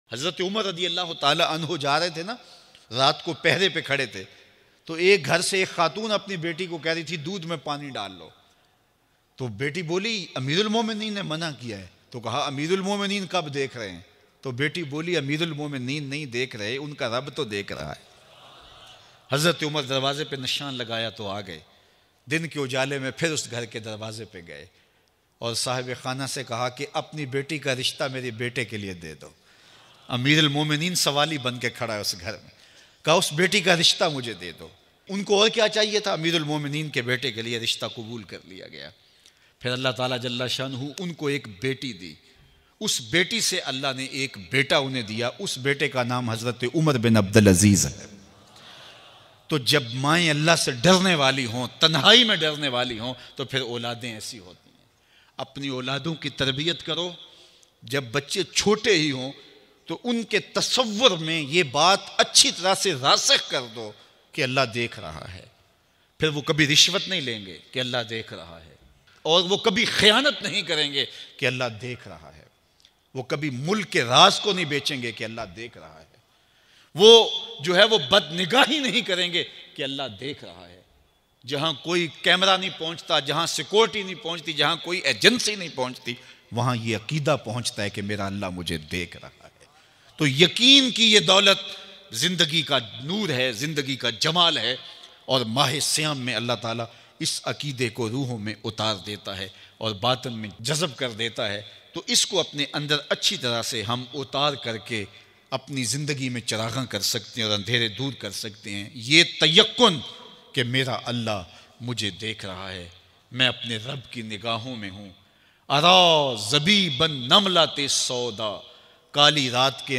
Hazrat UMER larki ka rishta mangne chale gye Bayan